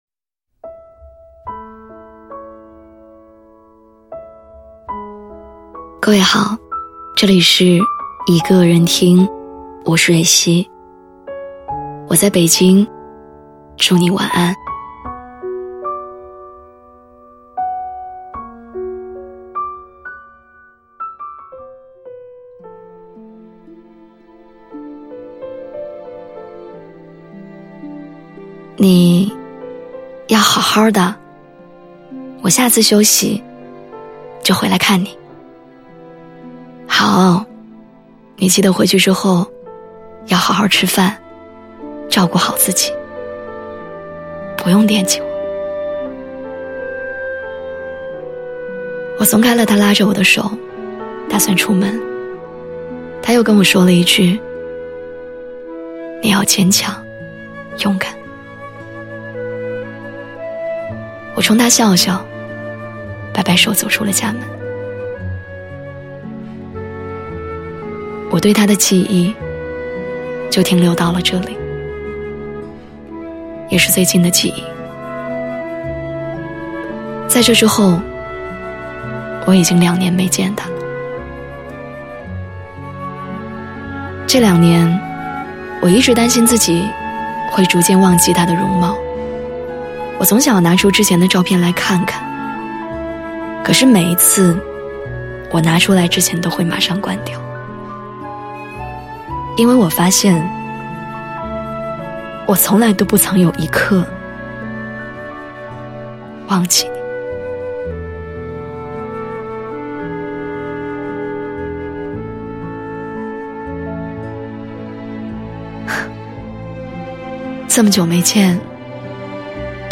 这是我第一次从头到尾哭着读完一个故事 谨以此篇文章献给那些我们已经失去了的亲人们